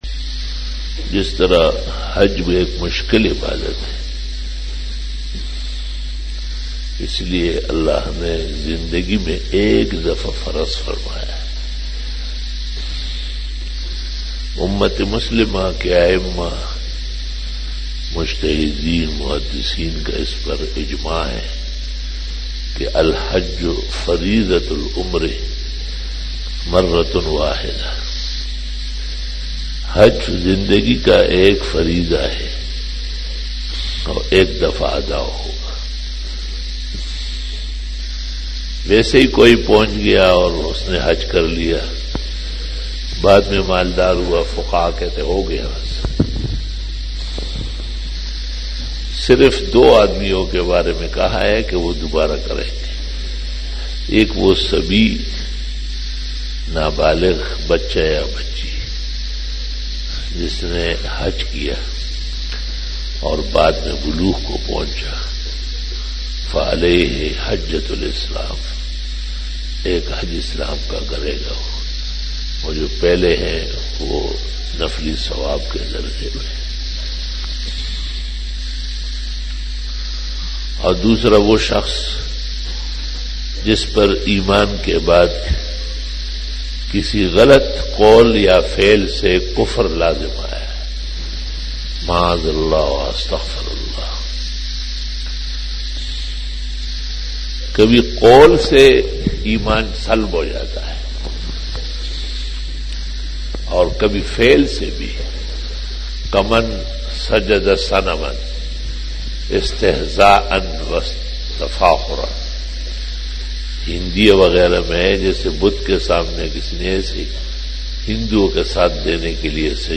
بیان بعد نماز فجر بروز بدھ 19 جمادی الاول 1441ھ/ 15 جنوری 2020ء"